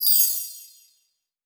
Fx [Chime].wav